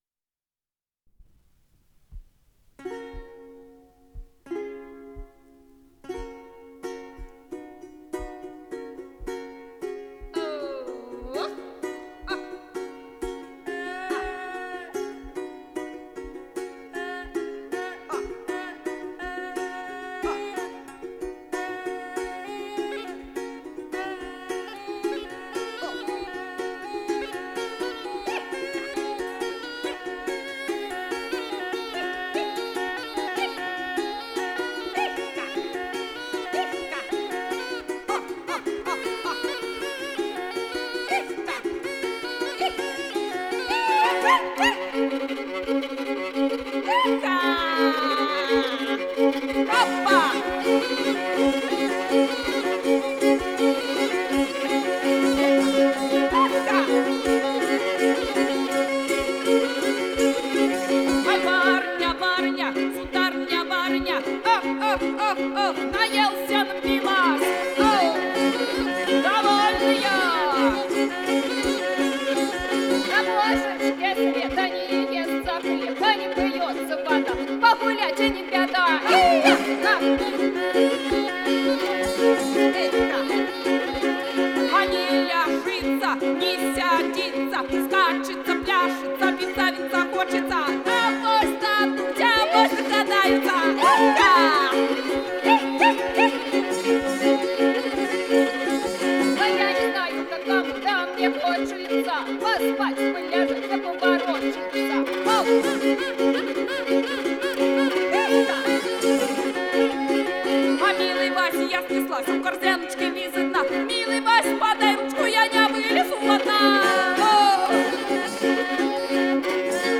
ИсполнителиФольклорный ансамбль "Славичи"